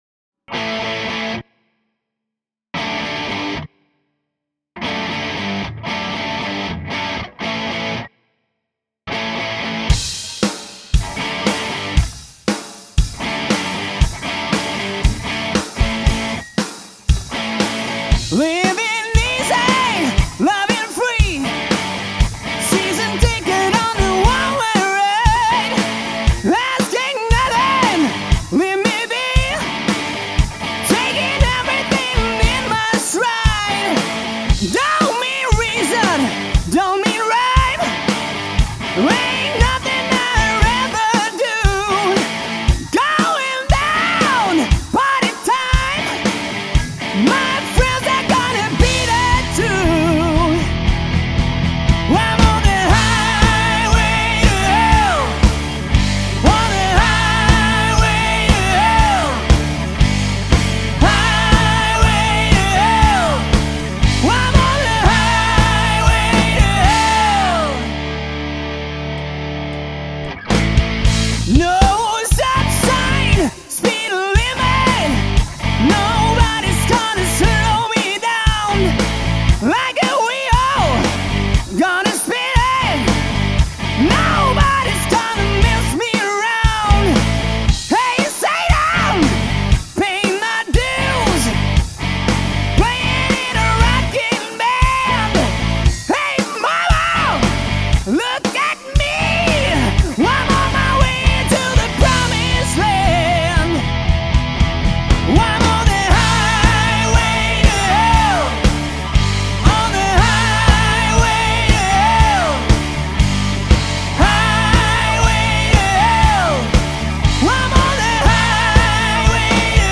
sång, piano.
gitarr.
bas.
trummor.